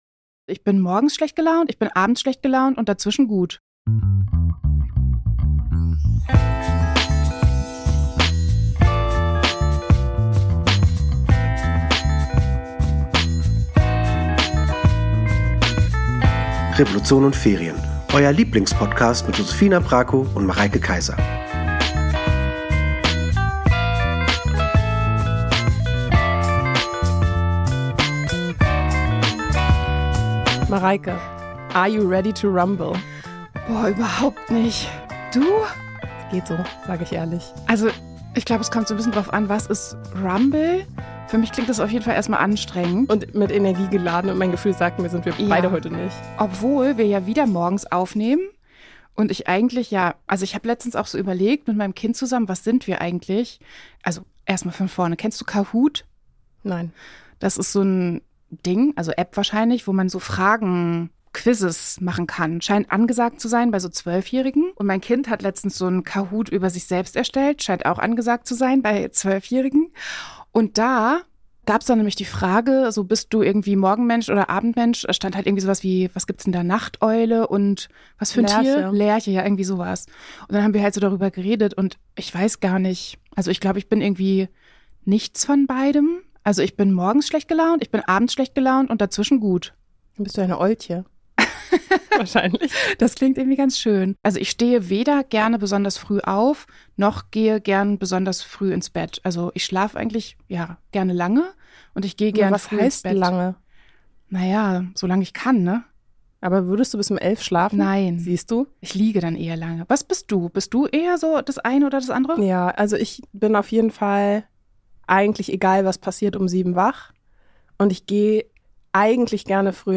Nachteule und Lerche treffen sich im Studio und sprechen übers Aufstehen und Liegenbleiben. Und übers Schreiben.